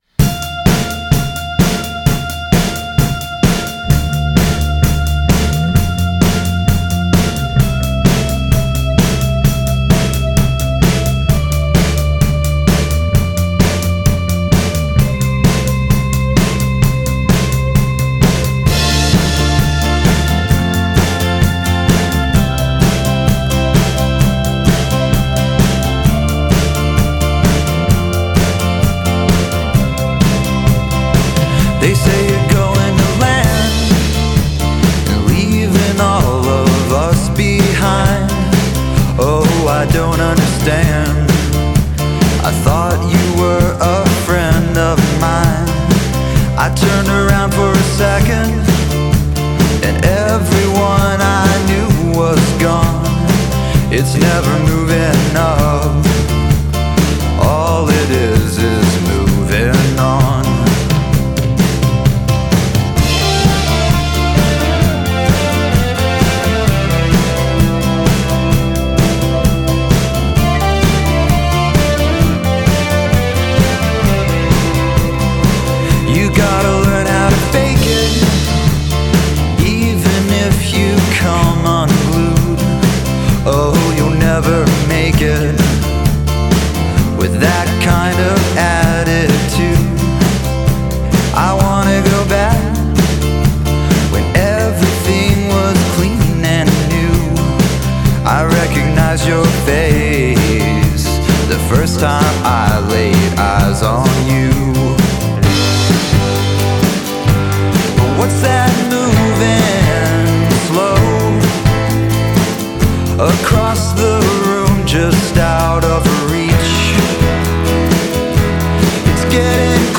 played drums.